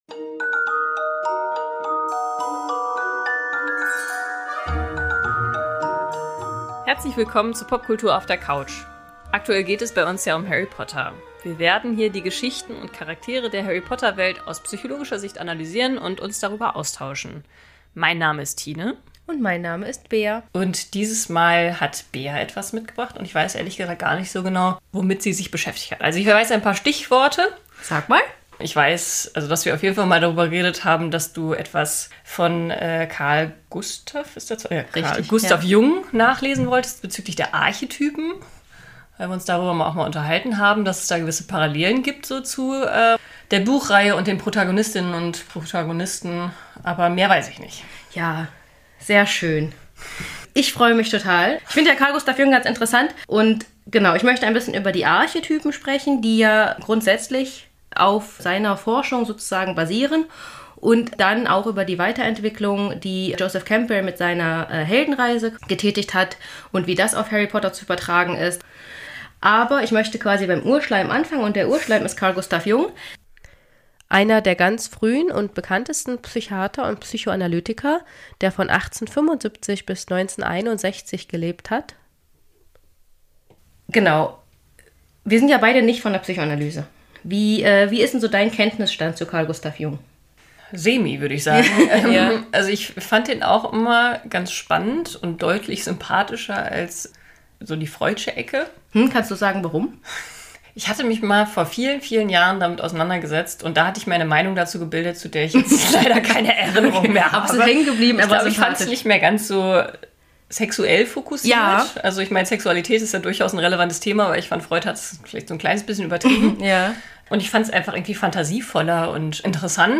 Achtung: Wir sind ja manchmal etwas dilettantisch im Schneiden, wundert euch nicht, diesmal habe ich 2 Snippets später eingefügt, ihr werdet es sicherlich auch merken...